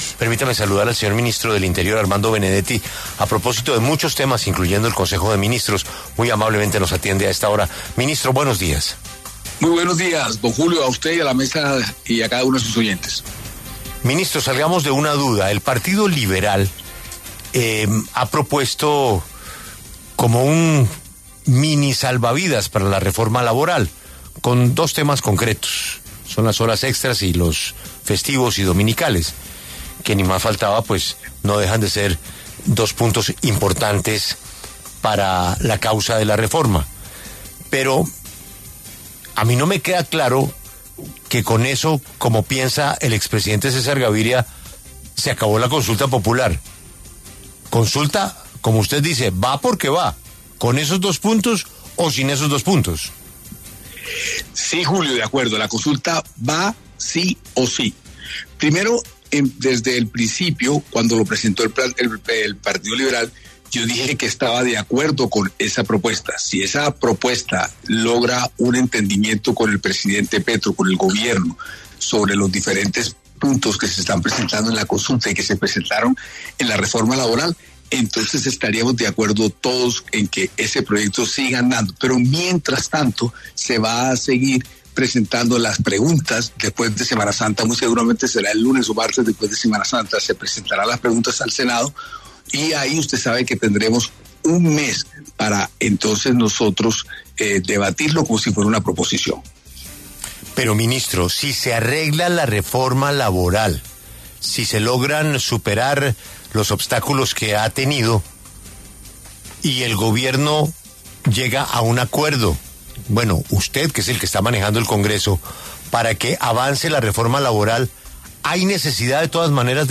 Armando Benedetti, ministro del Interior, habla en La W